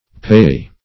Payee \Pay*ee"\, n.